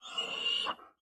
Minecraft Version Minecraft Version 1.21.5 Latest Release | Latest Snapshot 1.21.5 / assets / minecraft / sounds / mob / panda / worried / worried4.ogg Compare With Compare With Latest Release | Latest Snapshot
worried4.ogg